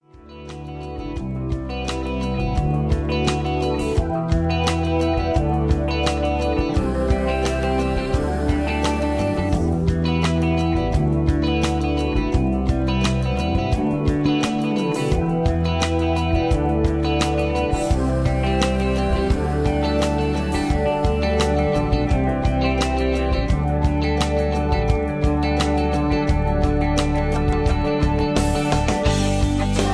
Key-Em) Karaoke MP3 Backing Tracks